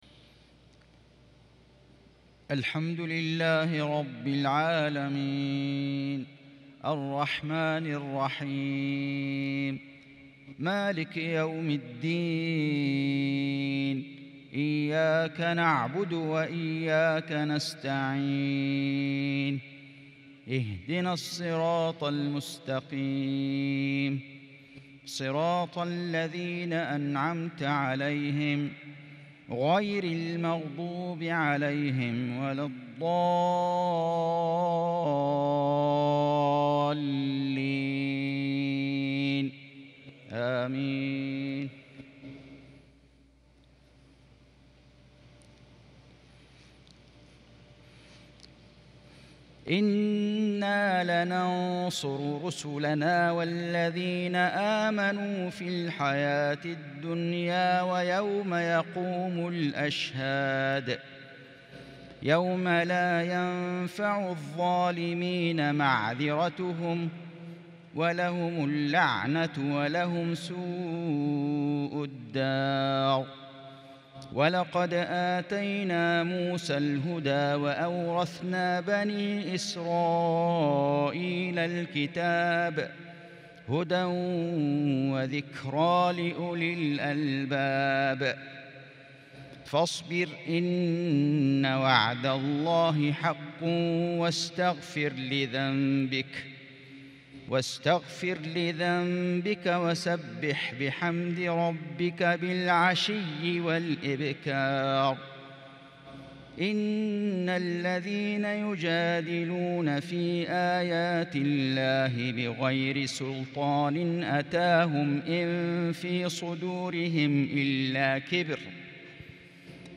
عشاء الخميس 22 شوال 1442هــ من سورة غافر | Isha prayer from Ghafir 3-6-2021 > 1442 🕋 > الفروض - تلاوات الحرمين